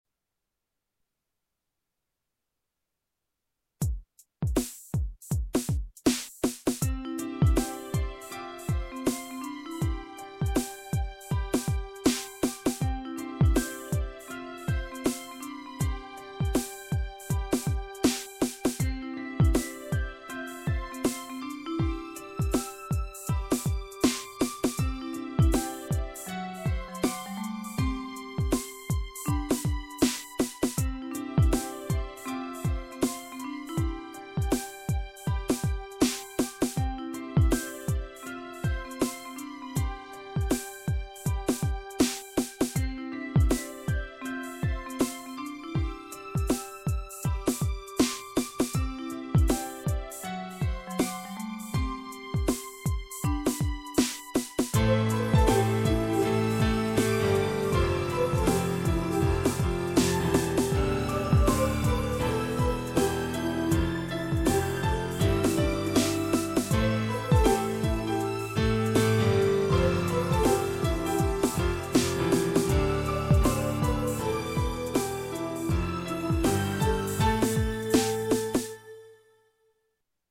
※素人が作った曲ですので音のバランスなどおかしいです。
※カセットテープの透明部分にかからないように曲の頭に数秒無音があります。故障じゃないです。 ご了承ください。
ちょっと切ない回想シーンへのつなぎの曲。